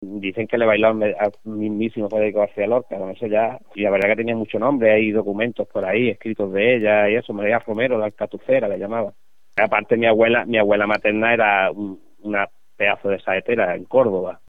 con orgullo de su origen formato MP3 audio(0,28 MB).